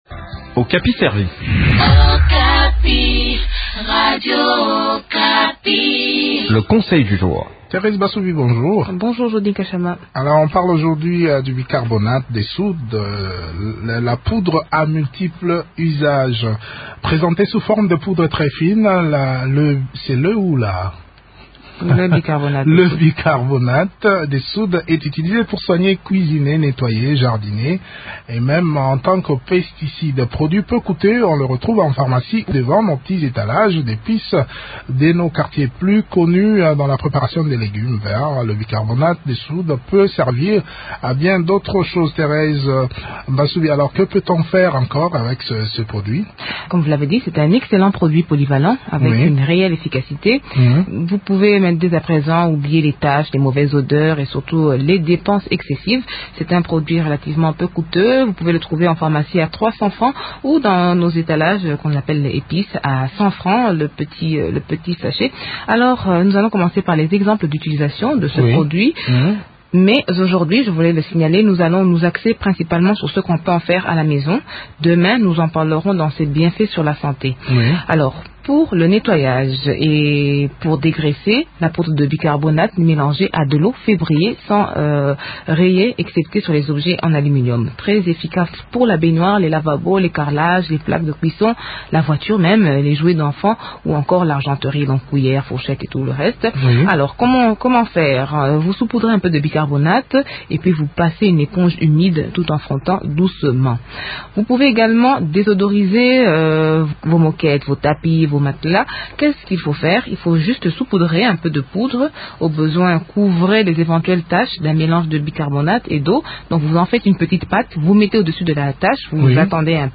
Constitué en poudre très fine, le bicarbonate de soude est utilisé pour soigner, cuisiner et nettoyer. Les jardiniers l’utilisent également dans leurs cultures. D’autres détails dans cet entretien